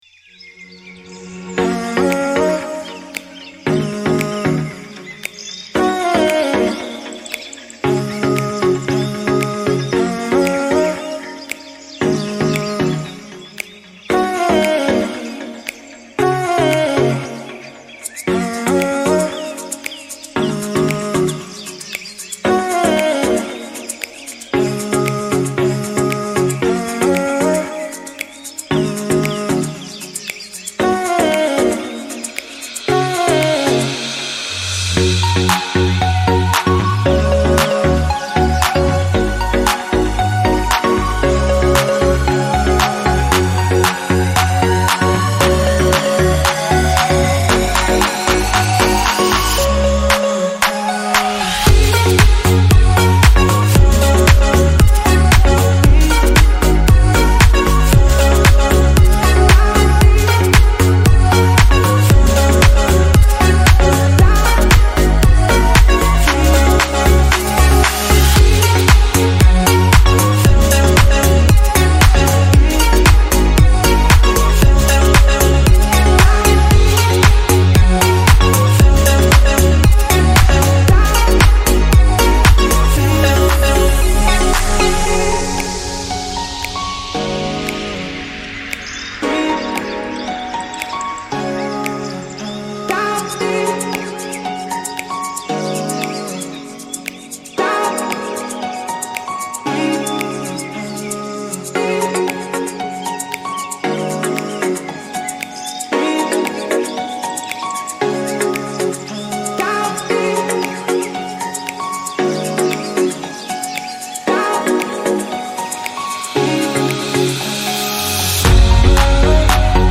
Счастливая фоновая музыка для детского праздника